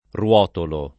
ruotolo [ r U0 tolo ]